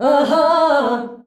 AHAAH E.wav